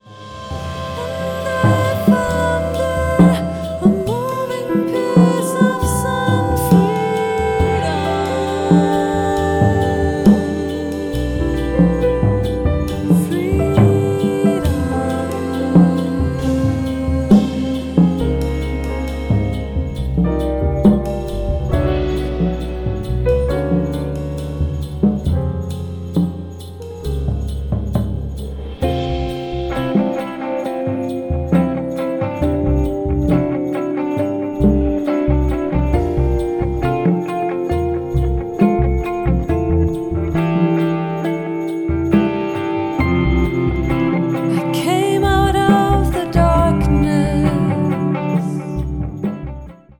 voice and live electronics
acoustic bass, electric bass
piano, Fender Rhodes, keyboards
drums and percussions